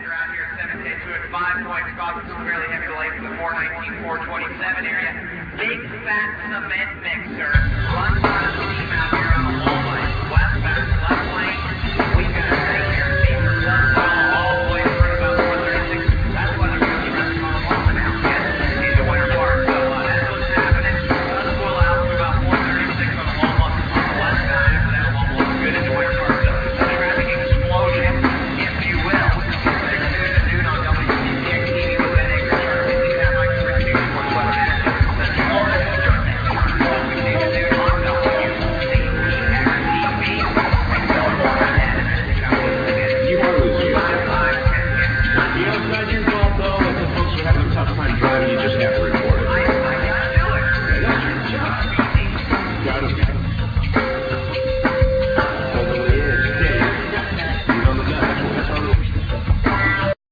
Trumpet
Turntables
Drums
Bass
Fender piano
Synthsizer, Hammond organ
African percussions
Guitar
Vocal, Violin